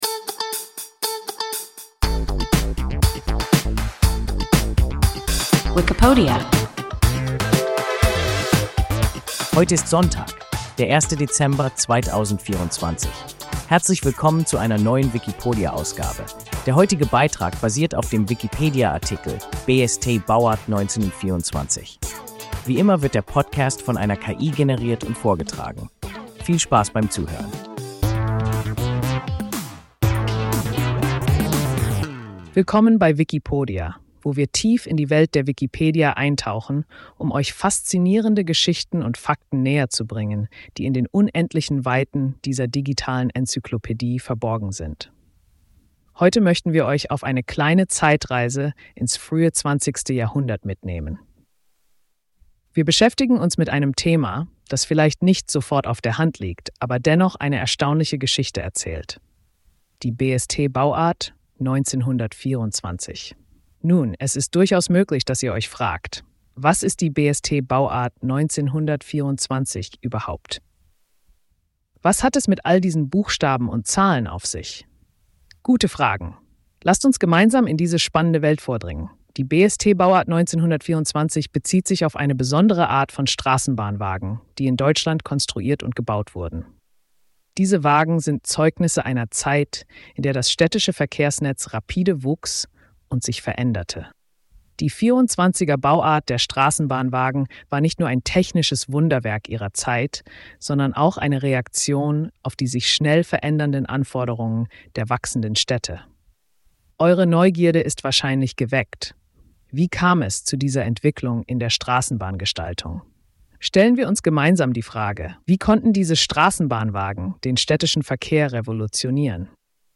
BSt Bauart 1924 – WIKIPODIA – ein KI Podcast